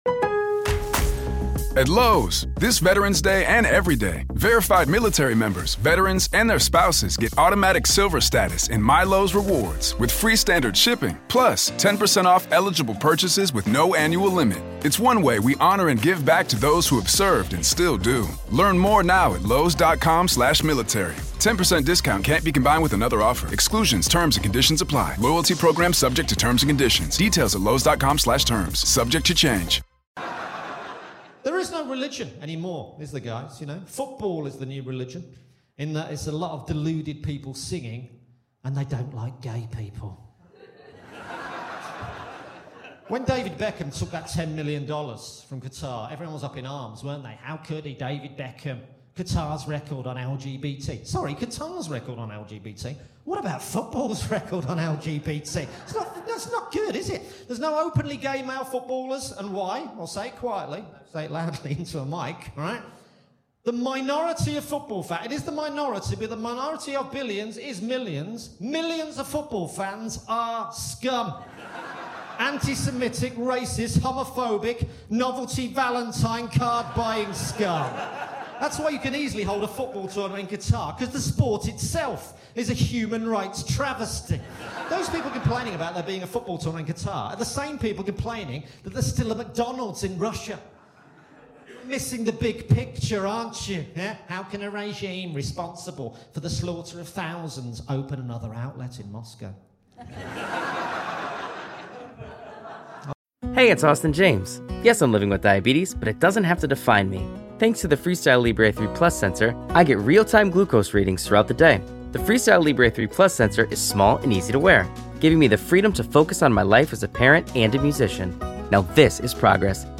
Recorded Live at Just The Tonic Edinburgh 2023.